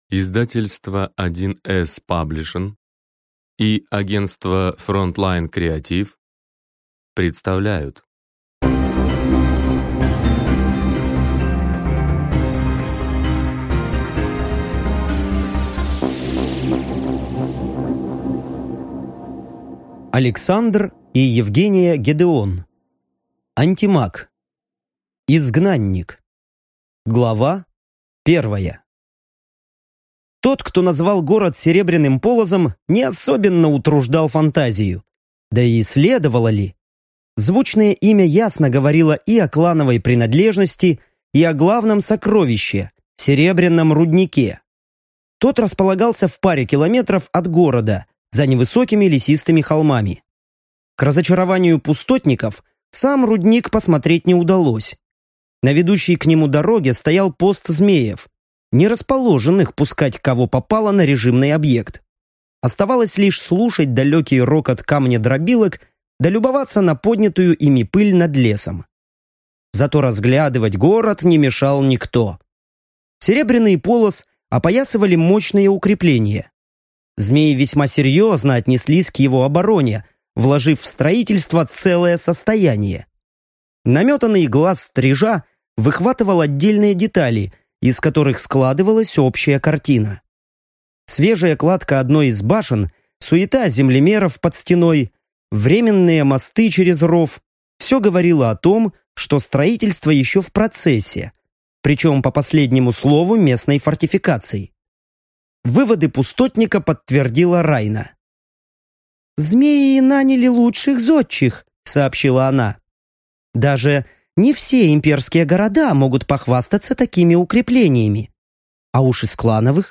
Аудиокнига Аудиокнига Александр и Евгения Гедеон «Антимаг. Изгнанник» Аудиокнига на Литрес с 28 .11.23 Главный герой становится жертвой террористического акта, и его душа «вытянута» в другой мир магами, намеренными использовать её в своих целях.